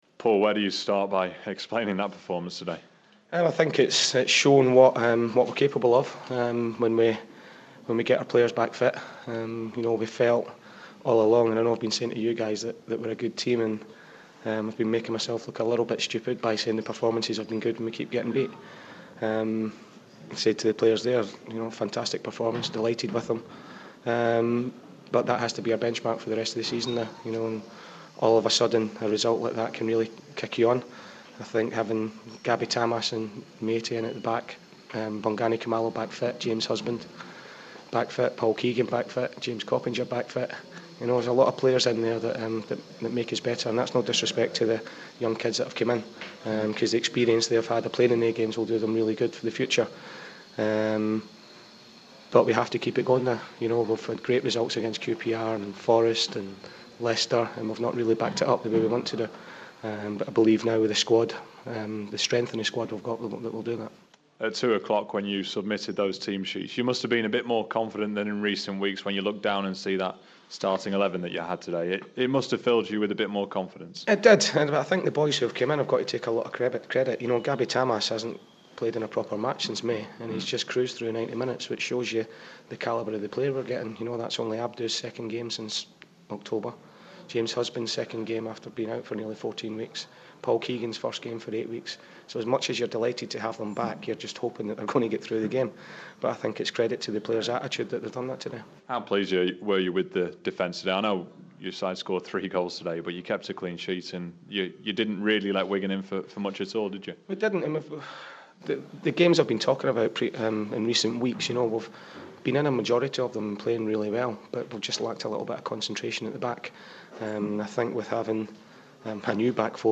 The Rovers boss speaking